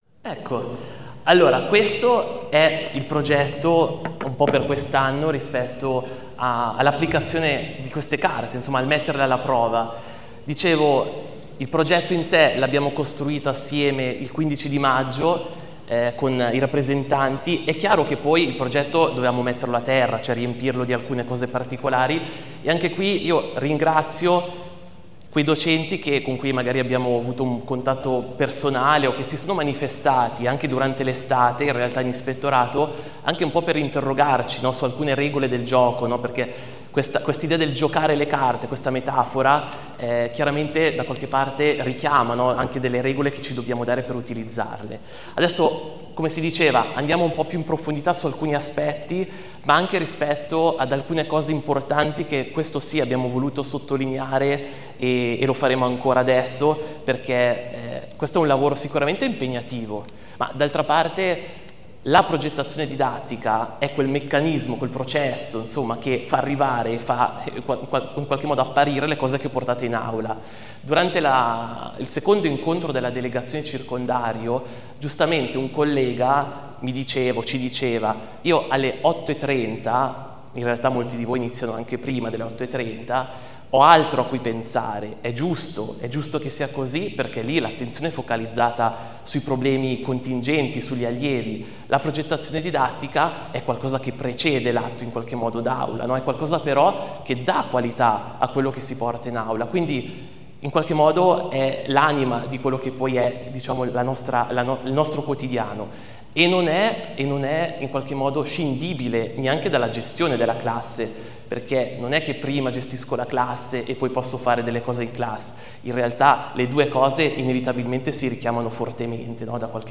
Presentazione